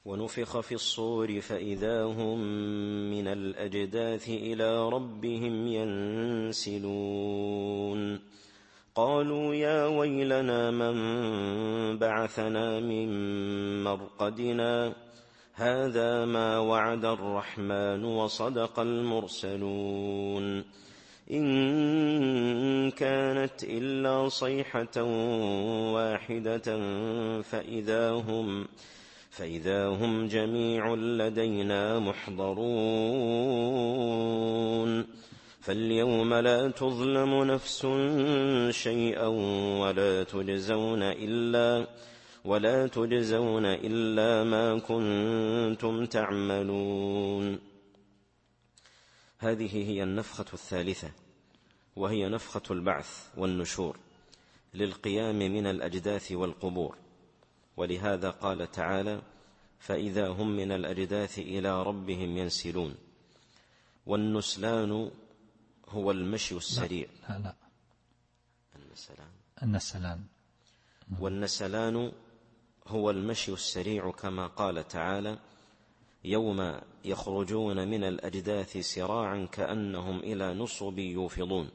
التفسير الصوتي [يس / 51]